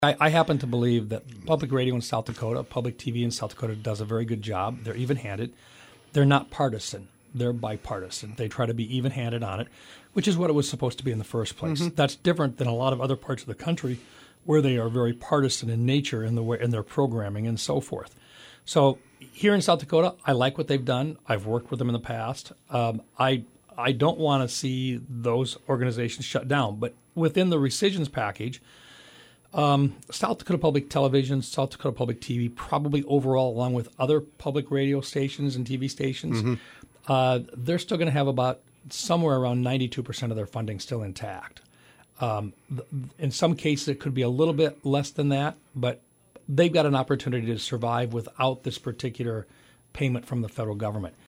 Recap of Senator Mike Rounds’ interview with Hub City Radio